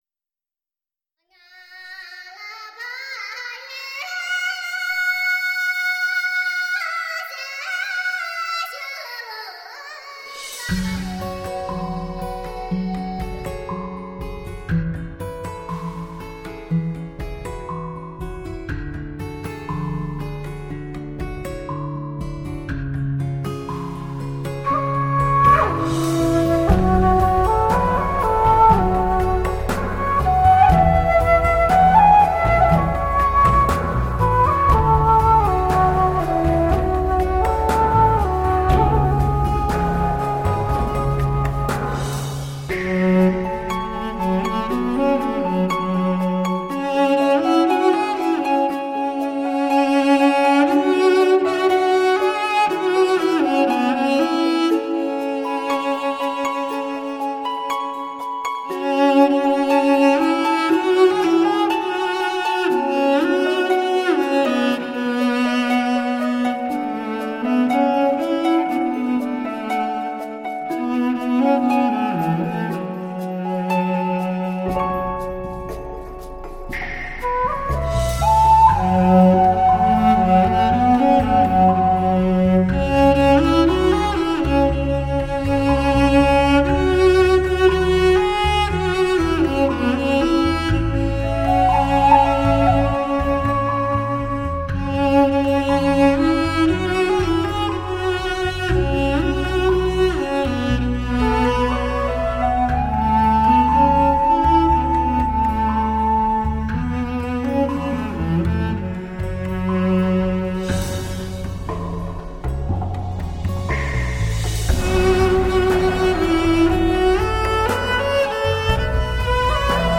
国际化的编曲，时尚的配器，耳目一新，优美绝伦！
你很熟悉的歌，纯音乐演绎，出乎意料的好听。
大提琴